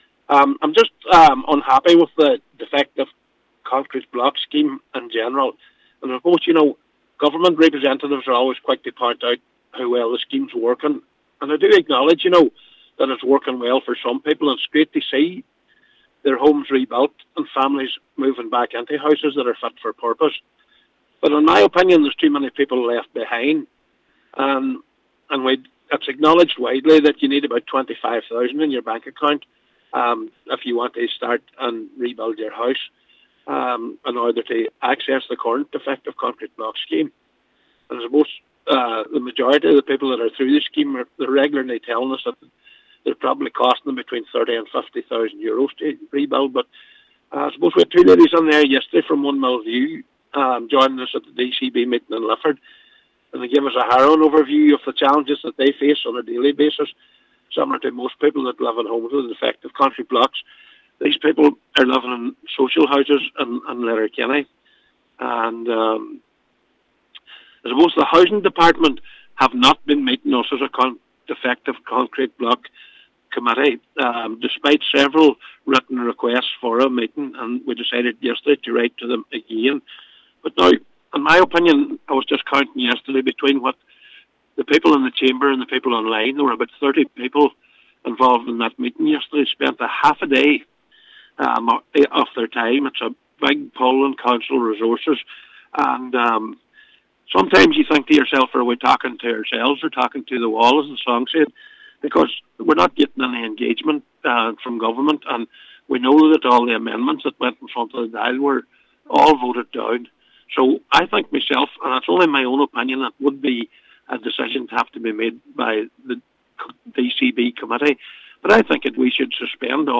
He says at times it feels like the committee is talking to itself when holding lengthy meetings: